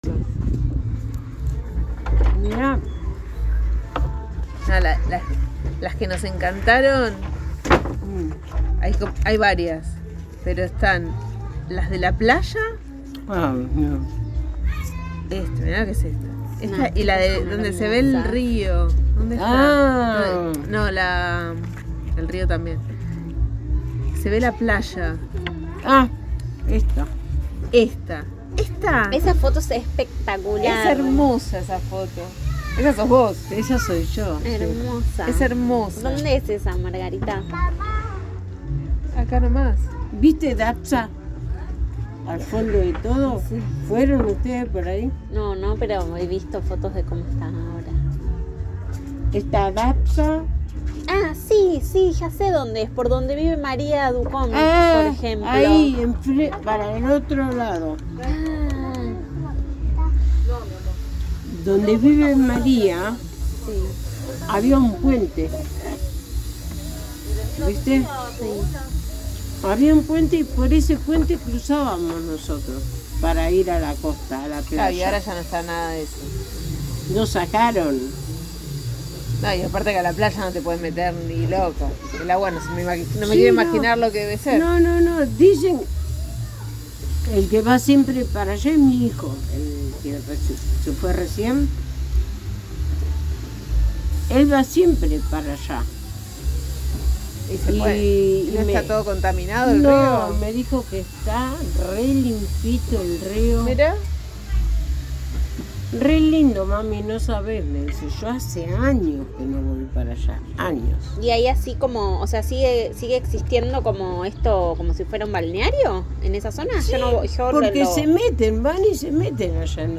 1 grabación sonora en soporte magnético.